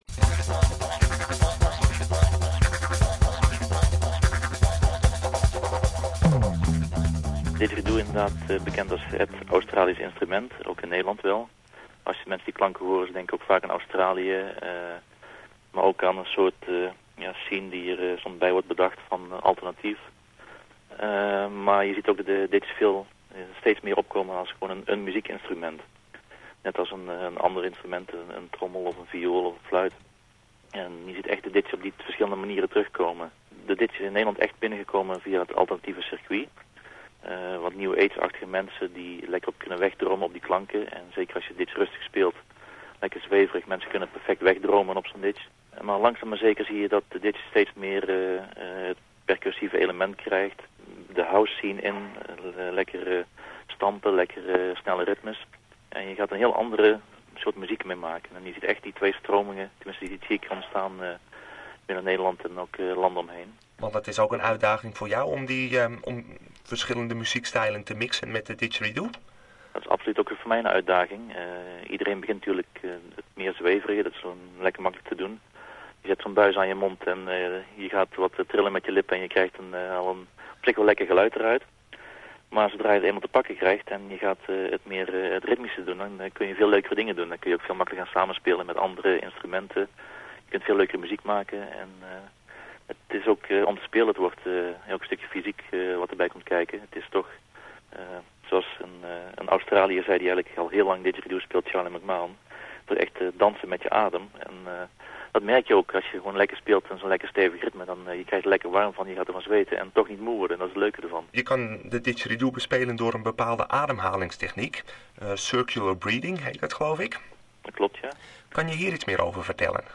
Het resultaat is een ca. 10 minuten durend interview dat is uitgezonden op SBS radio, in het blok voor de Nederlanders in Australie. Op de achtergrond hoor je muziek van Michael Jackson en Charlie McMahon.